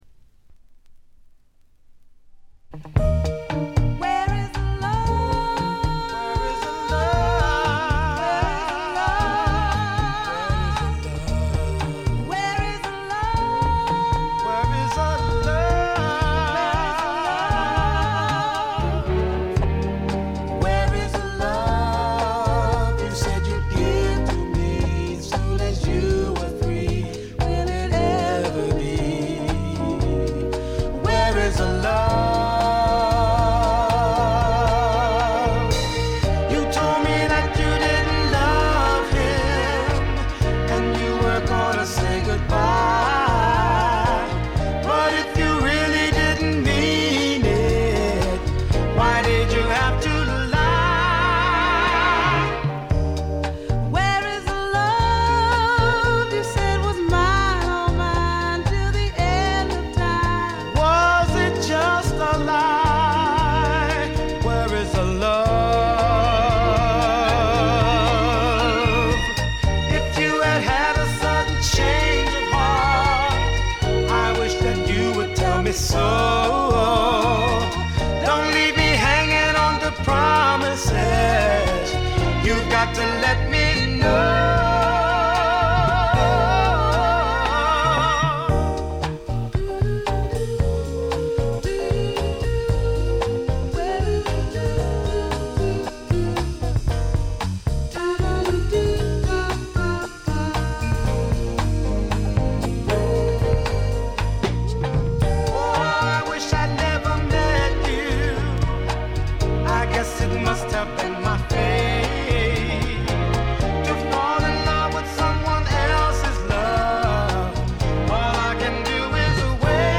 ごくわずかなノイズ感のみ。
頂点を極めた二人の沁みる名唱の連続でからだが持ちませんね。
試聴曲は現品からの取り込み音源です。